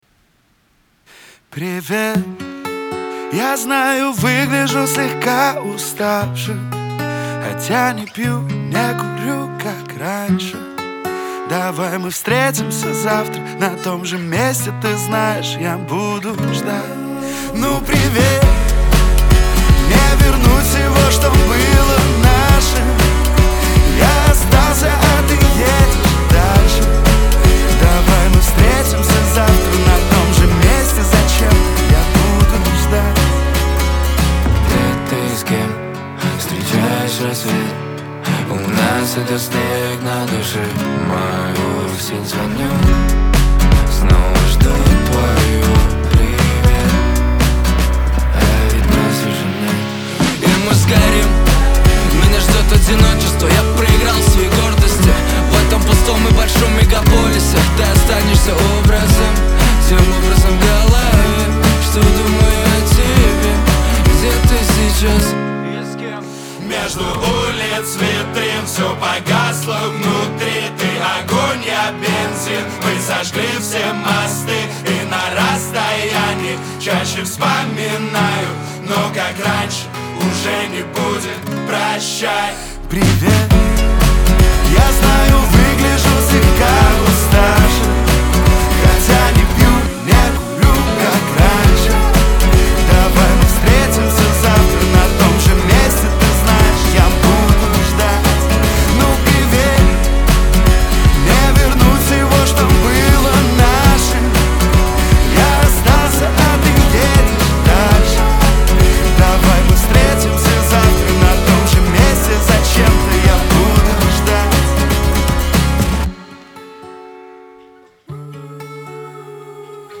Кавказ – поп